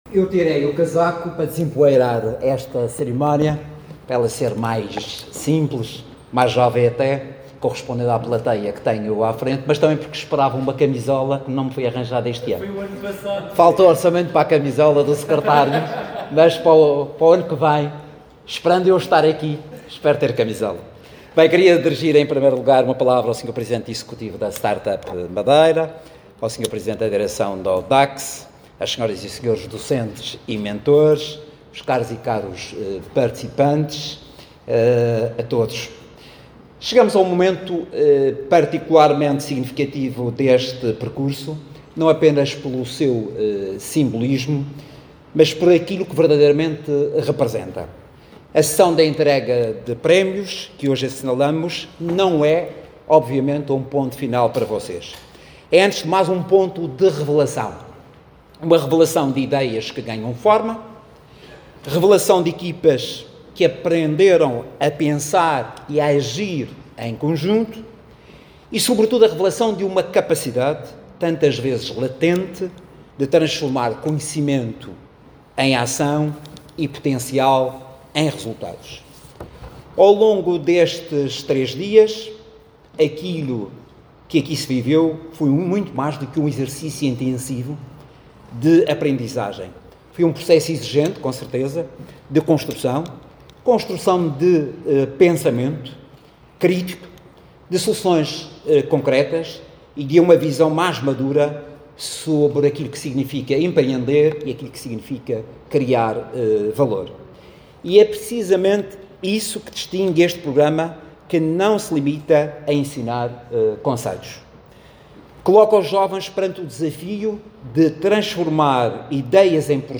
A intervenção teve lugar na sessão de encerramento da 16.ª edição do Curso Intensivo em Empreendedorismo e Inovação Empresarial, promovido pela Startup Madeira, no âmbito do rs4e, que reuniu 70 estudantes de cinco instituições de ensino superior e 22 áreas de formação distintas.